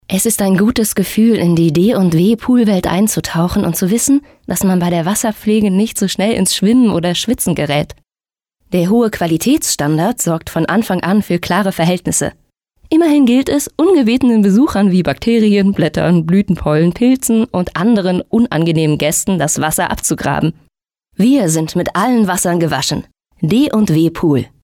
Sprechprobe: Werbung (Muttersprache):
Werbung_1.mp3